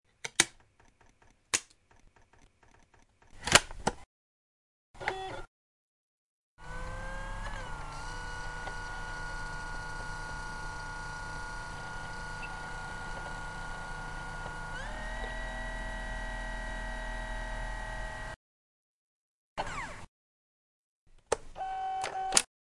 Download Dvd Player sound effect for free.
Dvd Player